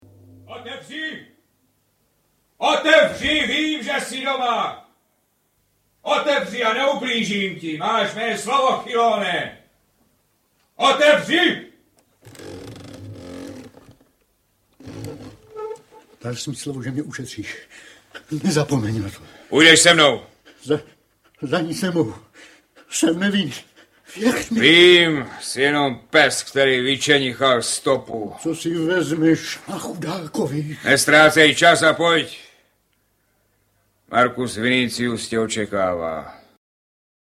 Quo vadis audiokniha
Ukázka z knihy